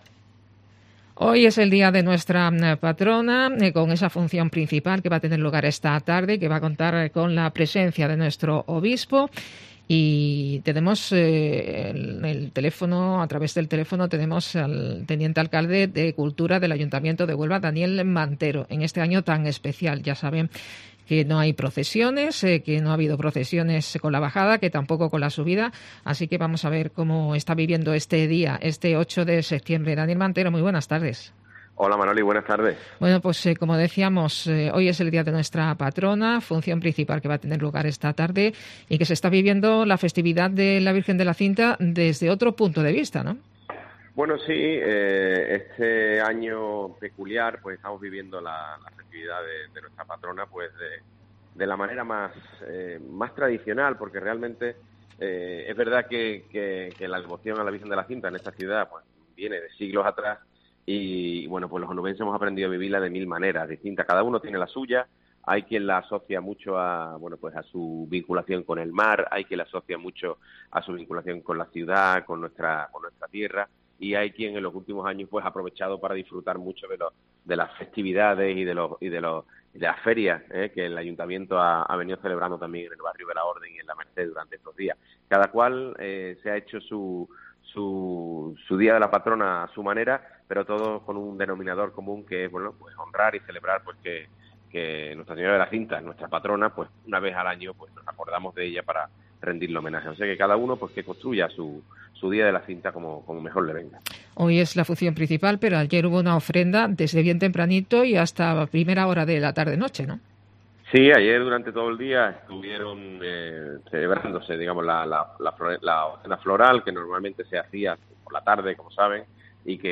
En este día especial de la Patrona de Huelva, en el tiempo local de Herrera en COPE hablamos con el teniente de alcalde de cultura del Ayuntamiento de Huelva, Daniel Mantero.